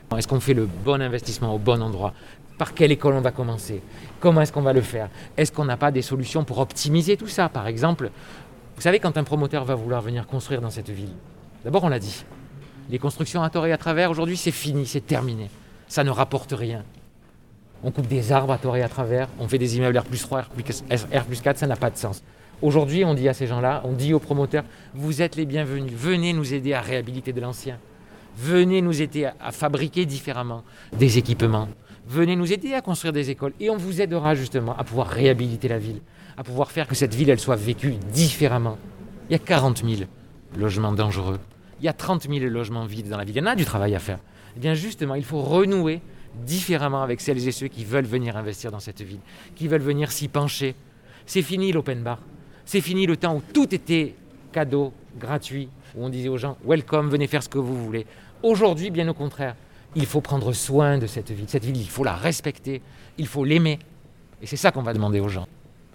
Entretien avec Benoît Payan